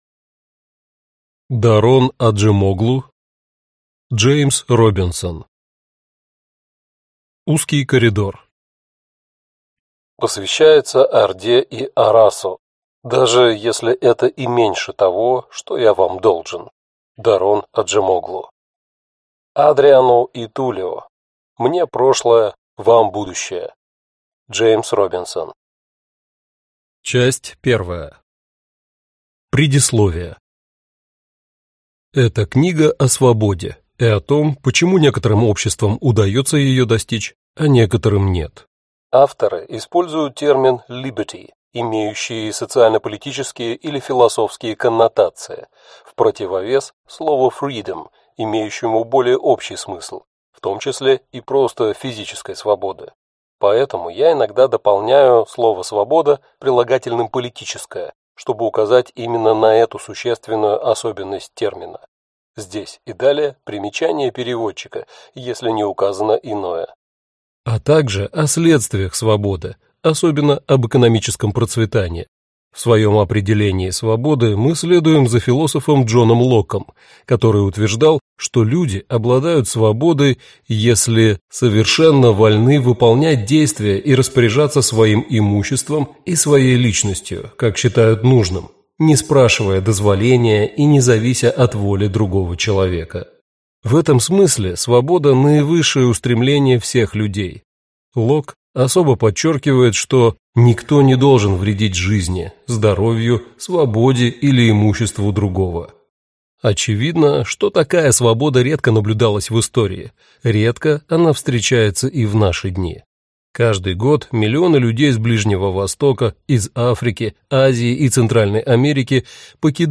Аудиокнига Узкий коридор. Часть 1 | Библиотека аудиокниг